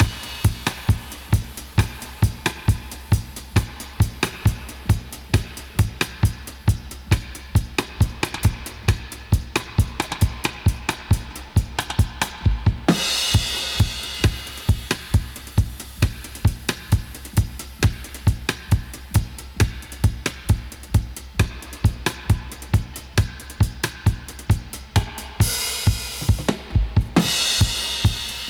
131-DUB-03.wav